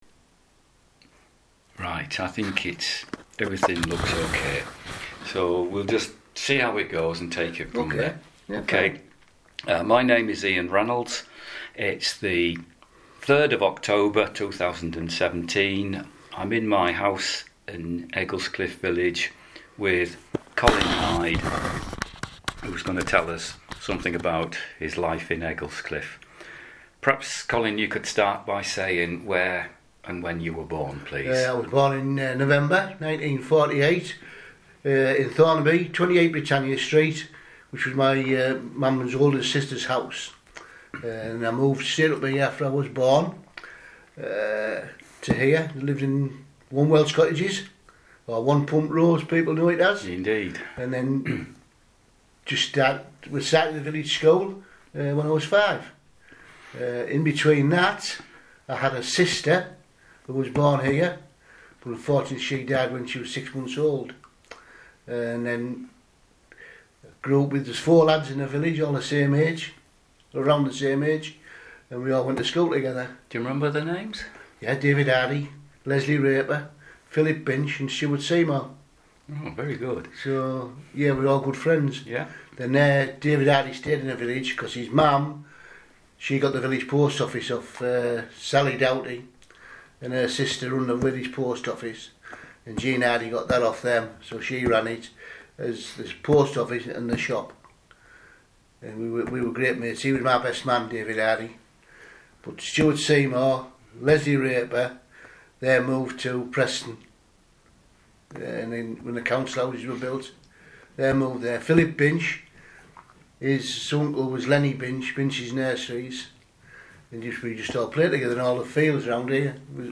oral history of living in Egglescliffe Village